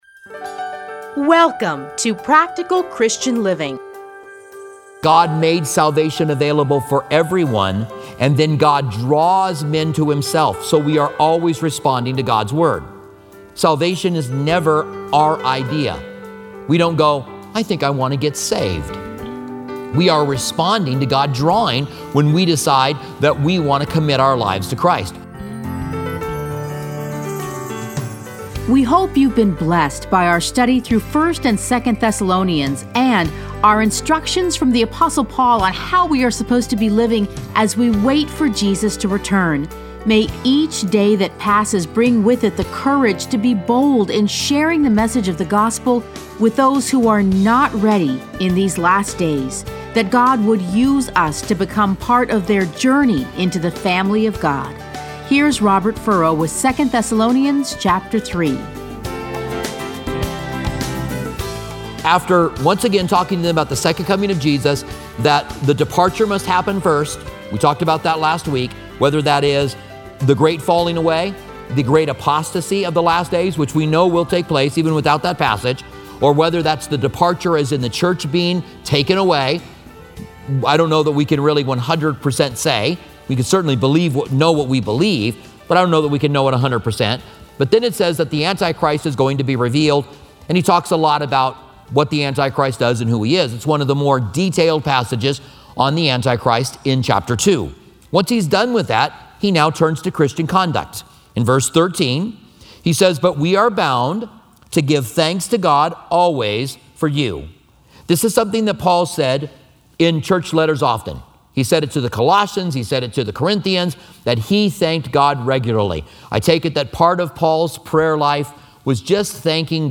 Listen to a teaching from 2 Thessalonians 3:1-18.